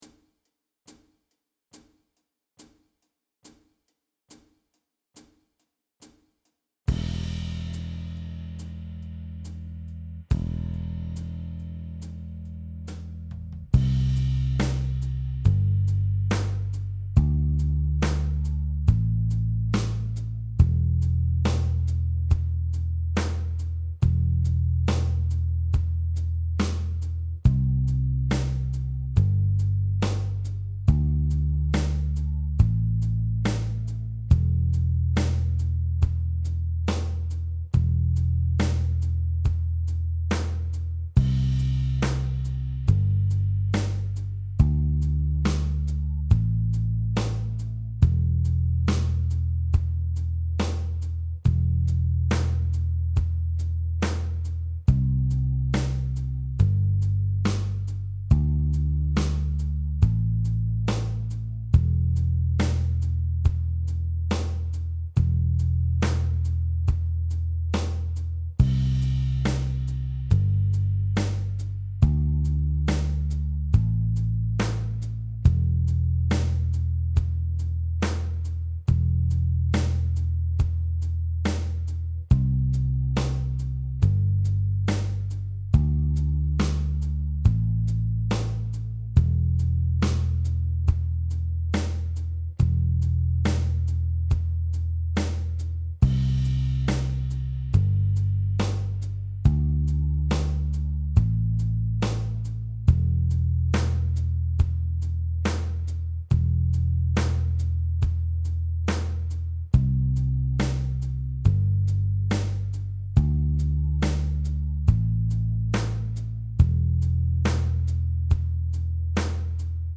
Play-Along Jam Track